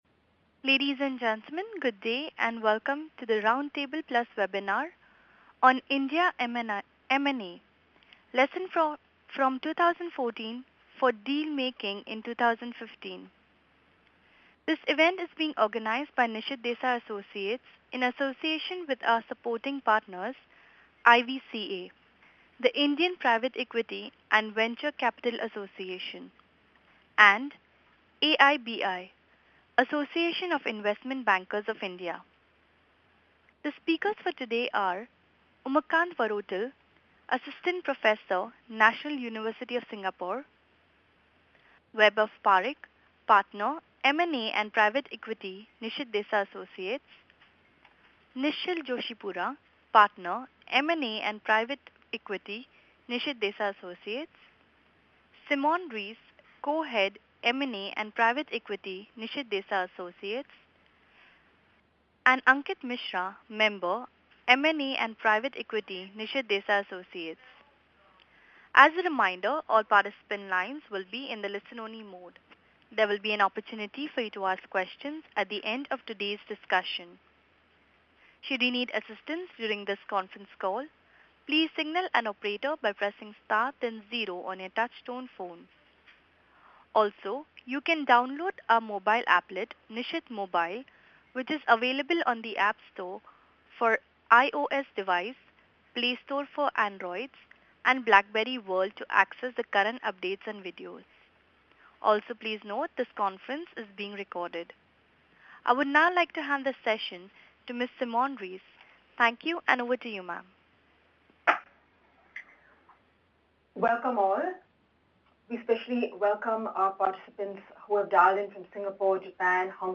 Round Table + Webinar: India M&A: Lessons from 2014 for Deal Making in 2015 (Tuesday, January 27, 2015)